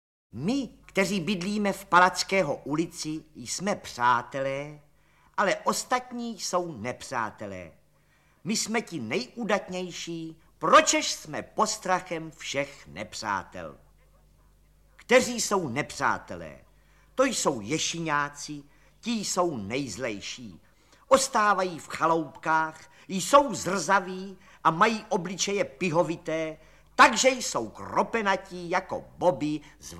Audio kniha
Ukázka z knihy
Slavný román Karla Poláčka proslul v šedesátých letech dnes již legendární interpretací Františka Filipovského. Původně rozhlasovou nahrávku vydává Supraphon v novém, rozšířeném vydání. Příběh rošťáren party chlapců, vyprávěný jedním z nich, malým Petrem Bajzou, známe také z televizní adaptace.
• InterpretFrantišek Filipovský